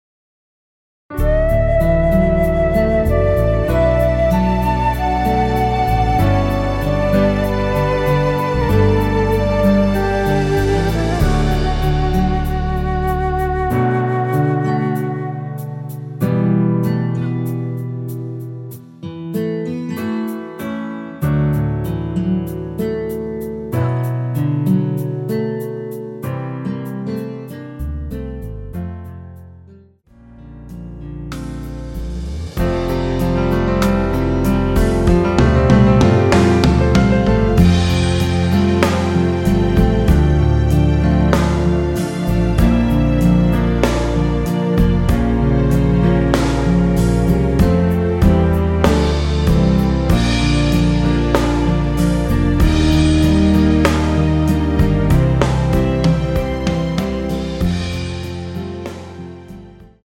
원키에서(-3)내린 MR입니다.
◈ 곡명 옆 (-1)은 반음 내림, (+1)은 반음 올림 입니다.
앞부분30초, 뒷부분30초씩 편집해서 올려 드리고 있습니다.